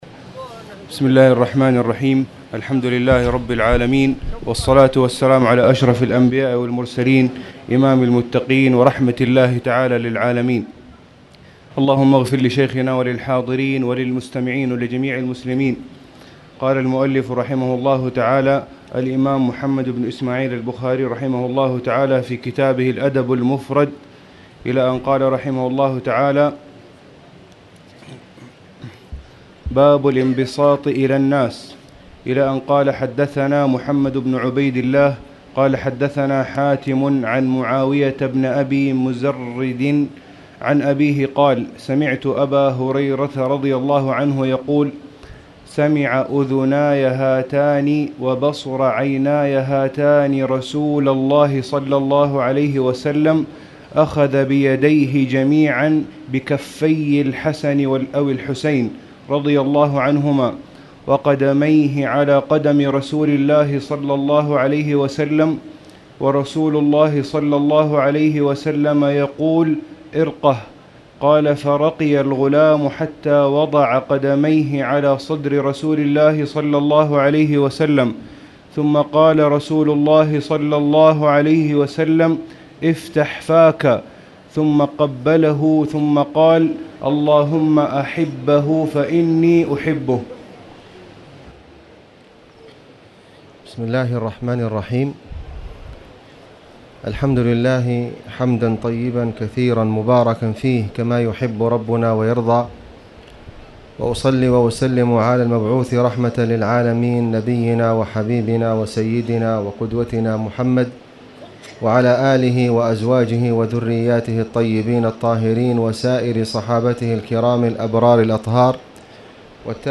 تاريخ النشر ٢٠ ربيع الأول ١٤٣٨ هـ المكان: المسجد الحرام الشيخ: فضيلة الشيخ د. خالد بن علي الغامدي فضيلة الشيخ د. خالد بن علي الغامدي التبسم